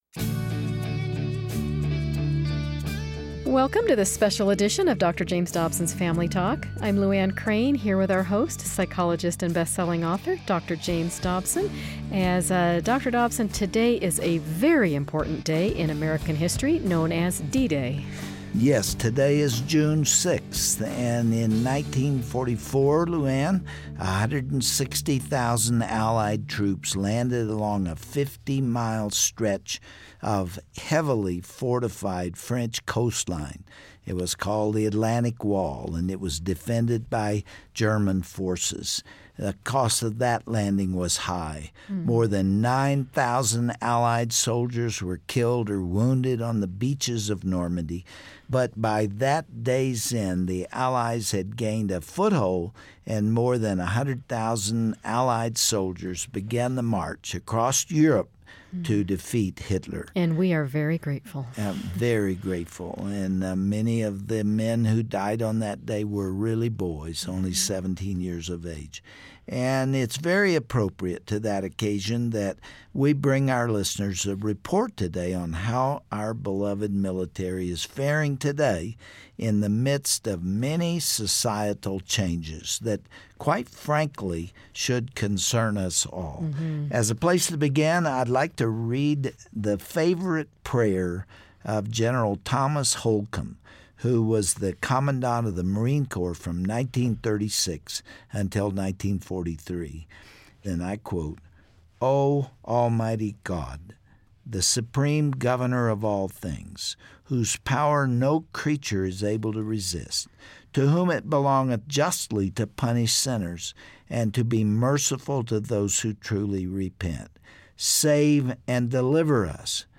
But, how is that culture faring in this postmodern society? Hear how our Armed Forces WILL be affected by the repeal of "Don't Ask Don't Tell" and the potential redefinition of marriage. It's a conversation every American should hear.